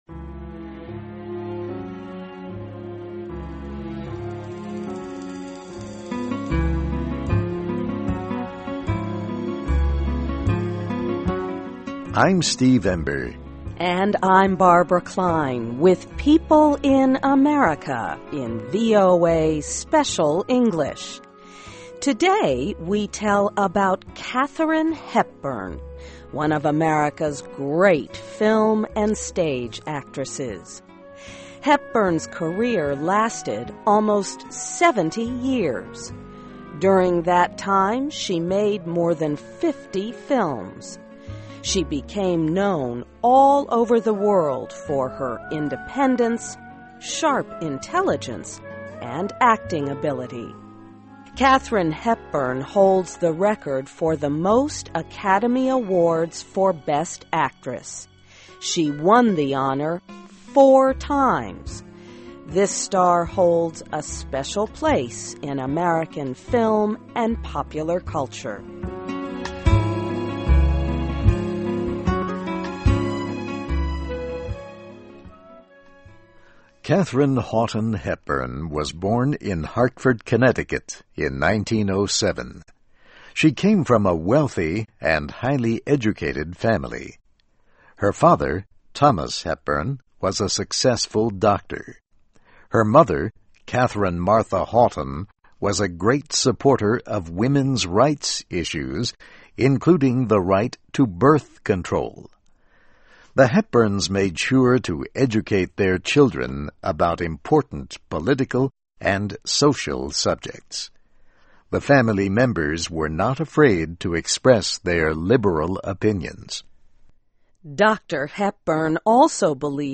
Here is a recording of Katharine Hepburn from a film about her life. She is talking about the values her family taught her.